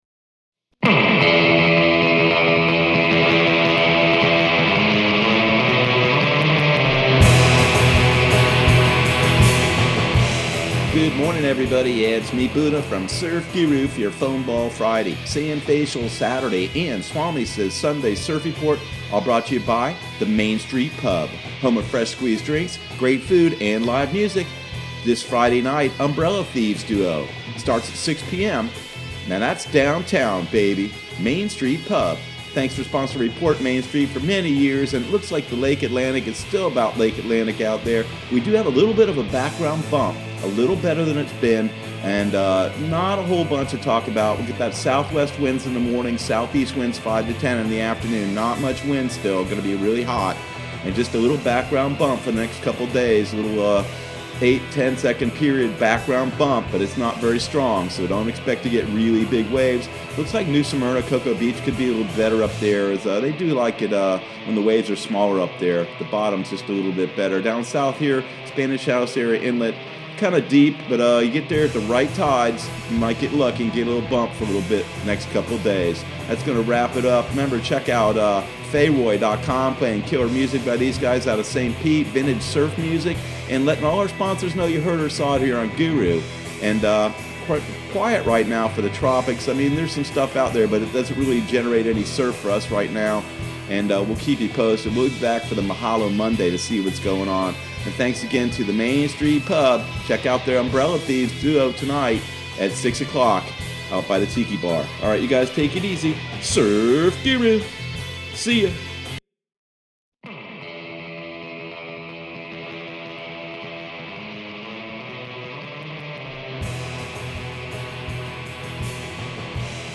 Surf Guru Surf Report and Forecast 09/04/2020 Audio surf report and surf forecast on September 04 for Central Florida and the Southeast.